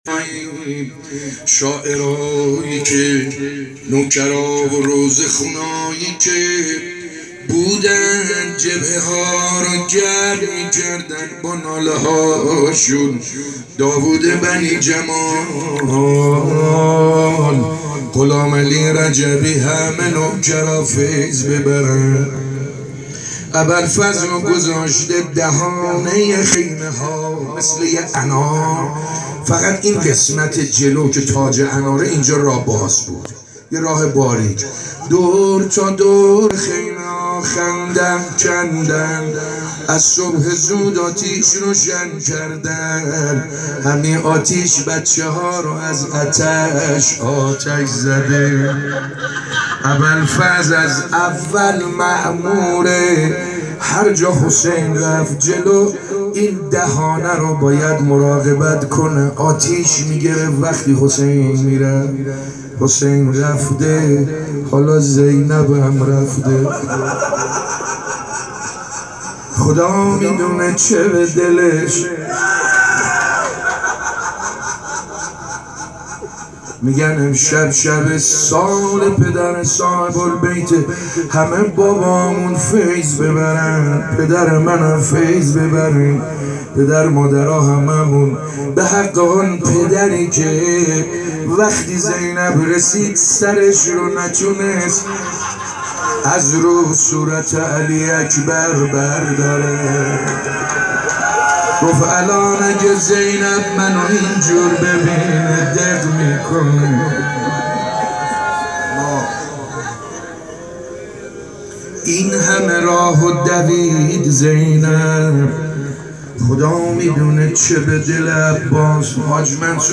روضه خوانی
عقیق : چهارمین شب از مراسم عزاداری سیدالشهدا در حسینیه فاطمه زهرا(س) با حضور صدها ارادتمند آستان سیدالشهدا برگزار شد .